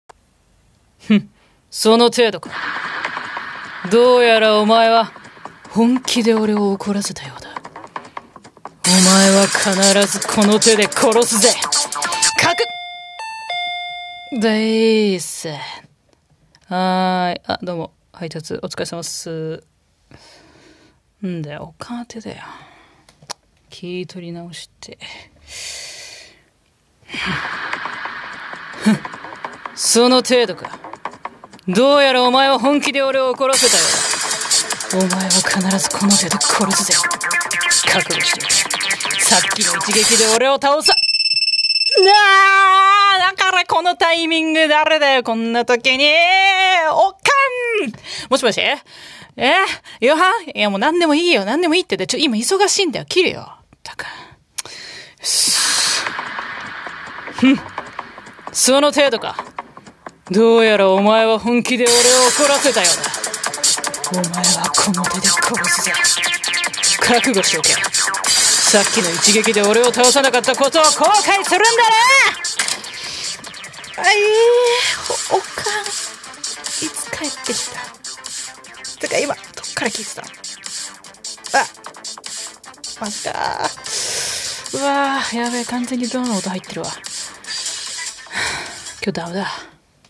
【1人声劇台本】「 trouble ×3 」